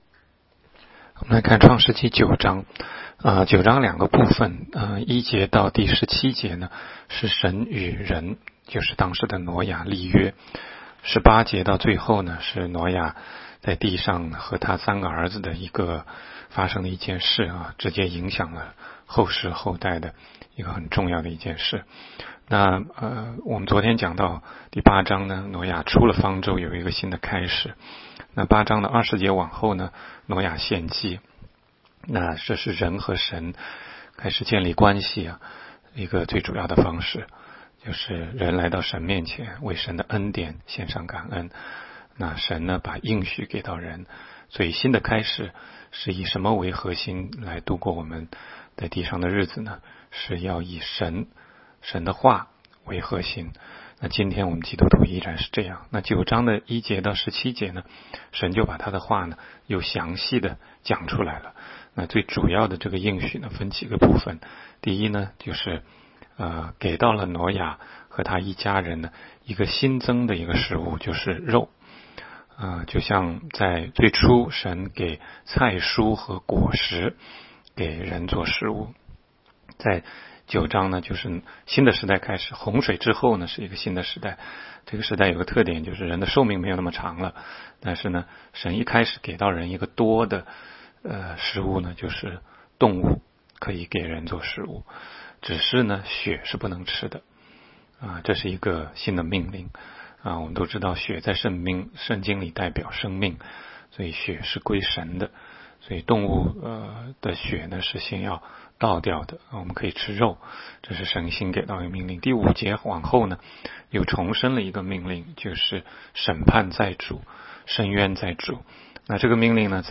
每日读经
每日读经-创9章.mp3